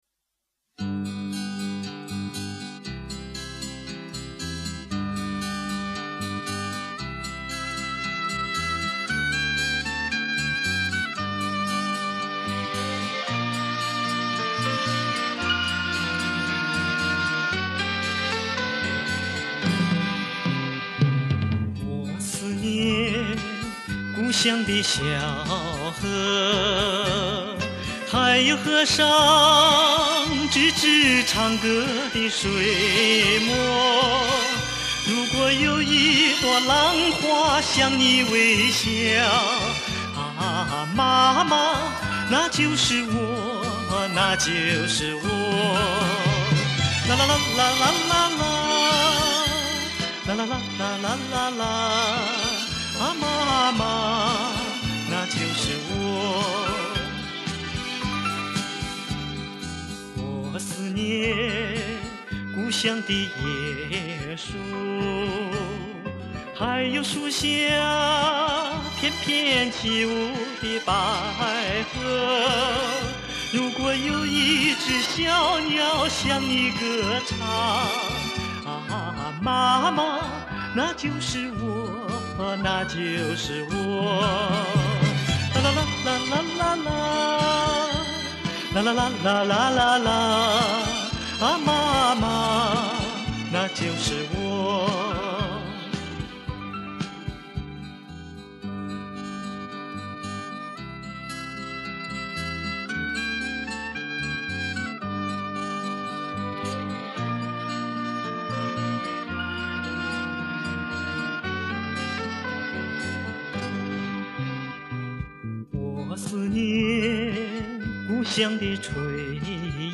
走的是民谣小清新风格的路线